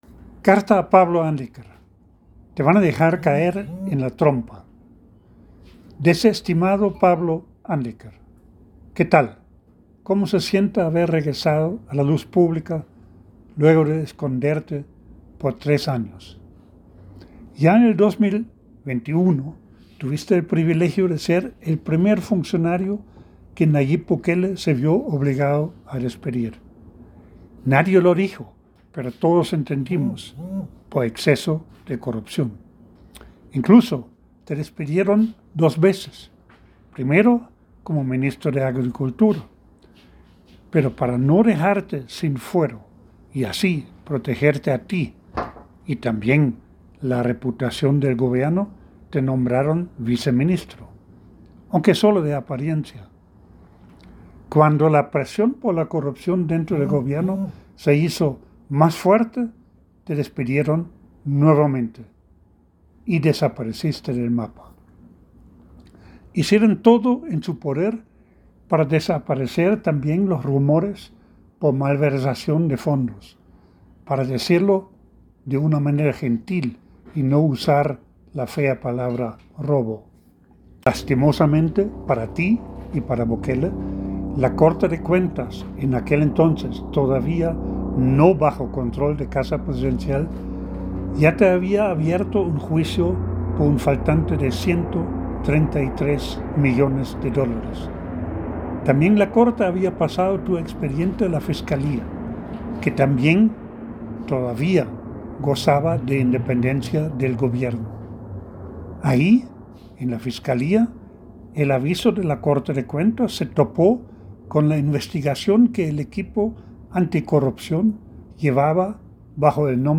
El audio en la voz del autor: